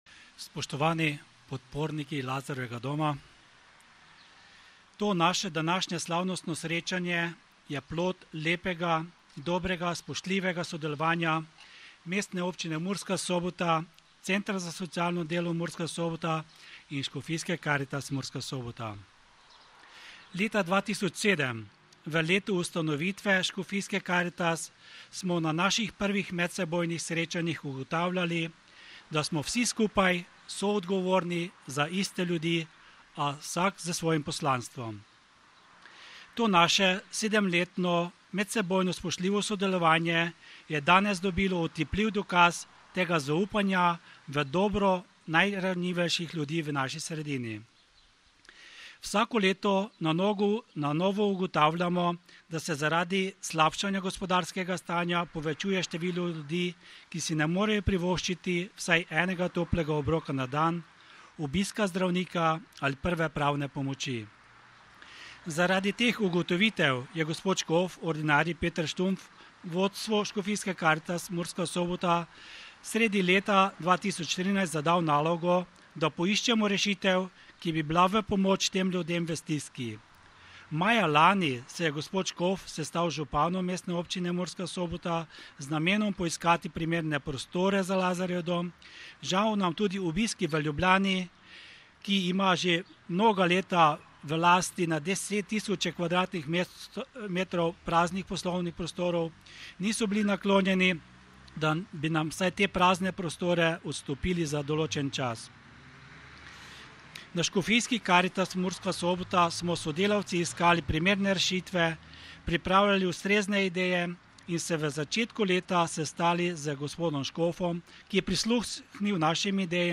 11.00 blagoslovitev Lazarjevega doma
Audio nagovora